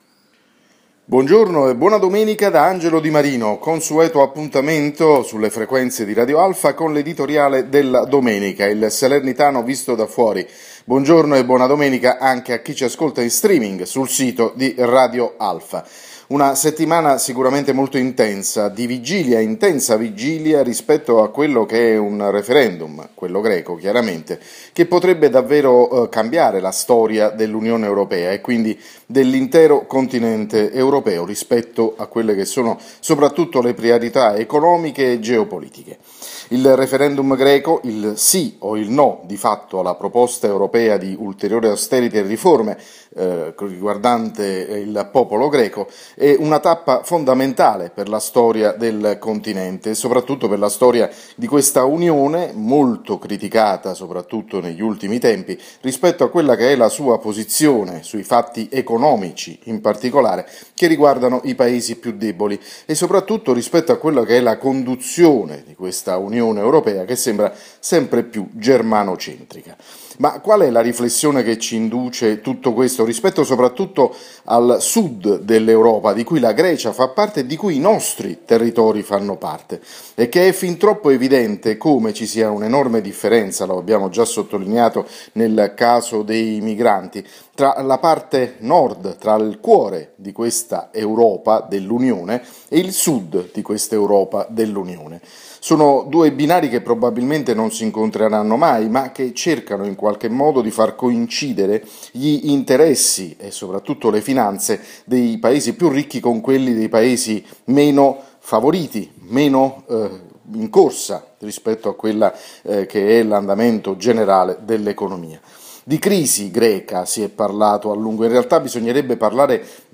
L’editoriale della domenica andato in onda sulle frequenze di Radio Alfa questa mattina.